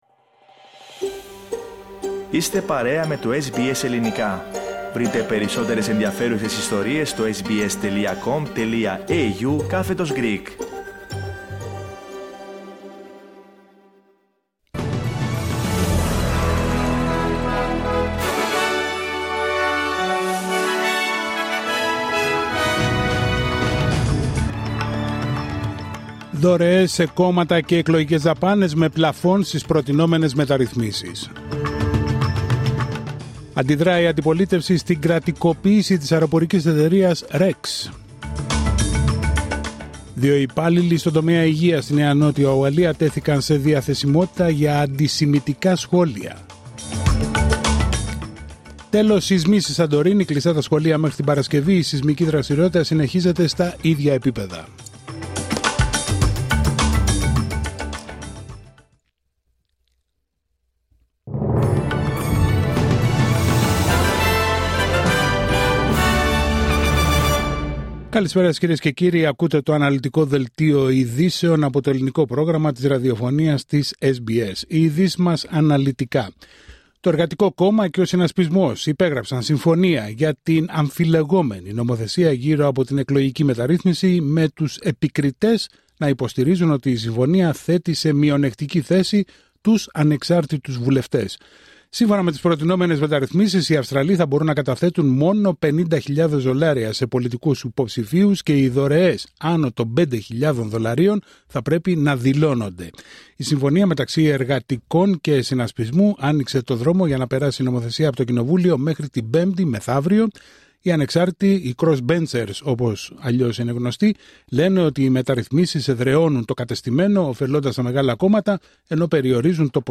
Δελτίο ειδήσεων Τετάρτη 12 Φεβρουαρίου 2025